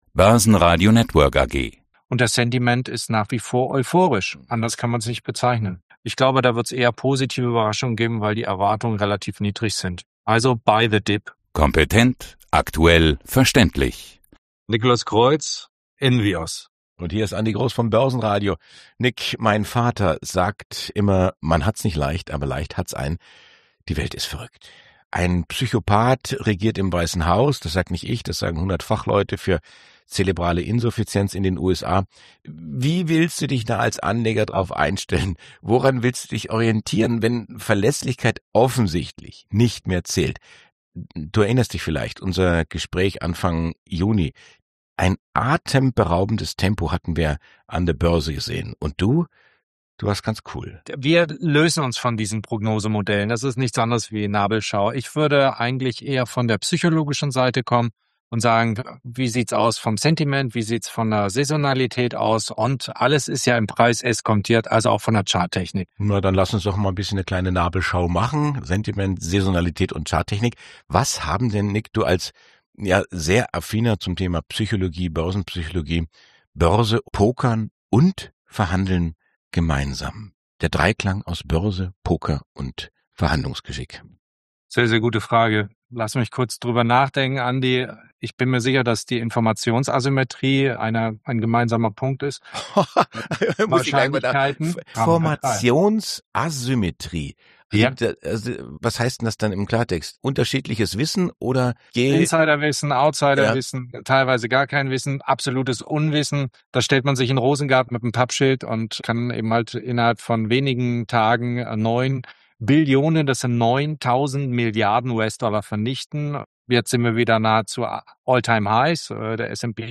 Zum Börsenradio-Interview